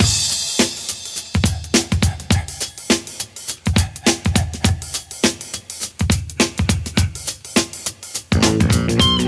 Free drum beat - kick tuned to the F note. Loudest frequency: 1149Hz
• 103 Bpm Drum Loop Sample F Key.wav
103-bpm-drum-loop-sample-f-key-JoJ.wav